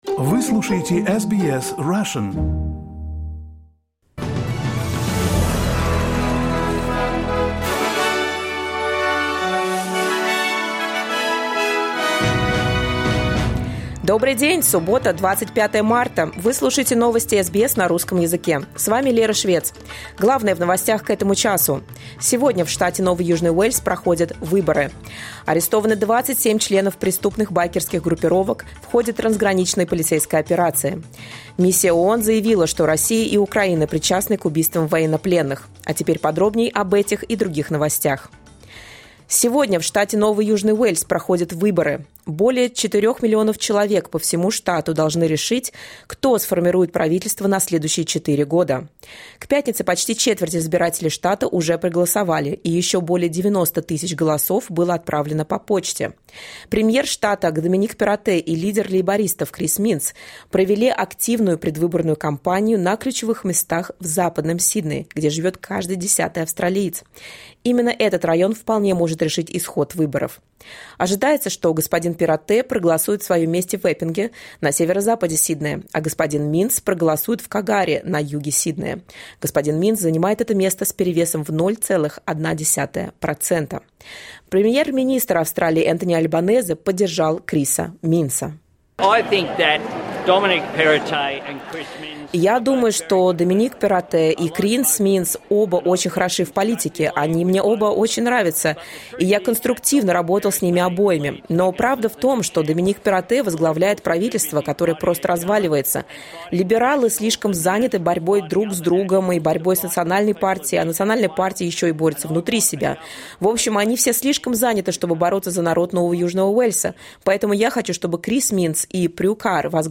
SBS news in Russian — 25.03.2023